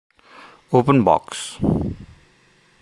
open_box.abb2171a.mp3